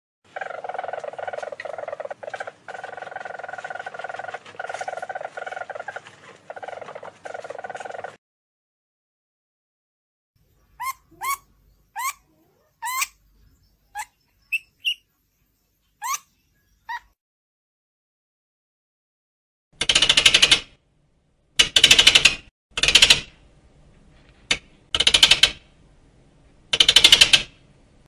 Donnola
DONNOLA-Mustela-nivalis.mp3